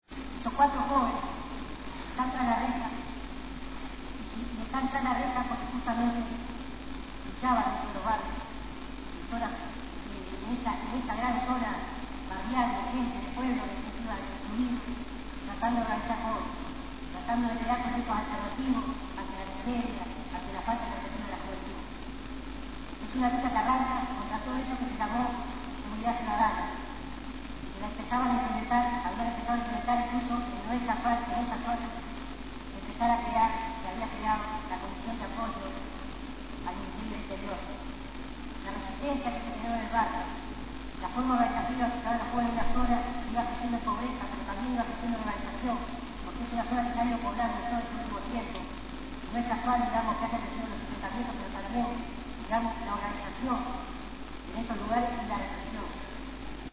A continuación extractos grabados por Indymedia/Uruguay de las palabras dichas por